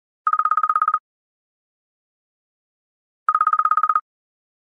Category: iPhone Ringtones